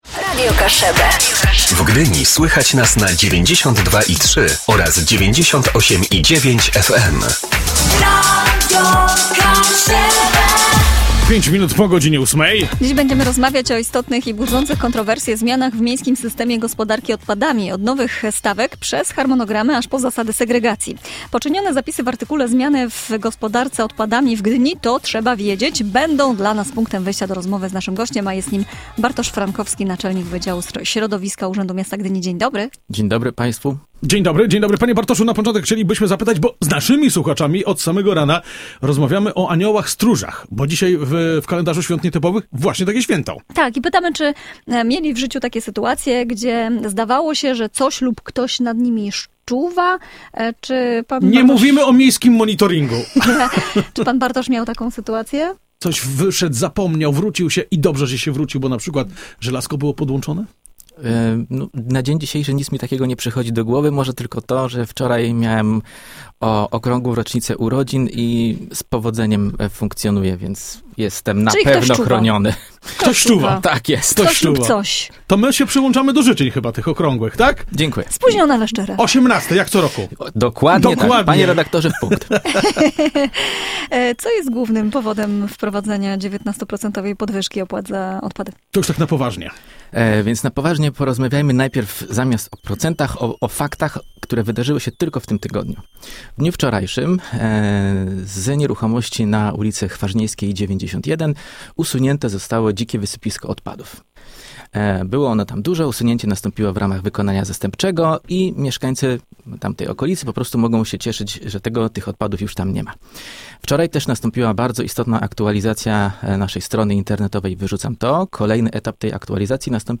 rozmowa-gdynia-smieci.mp3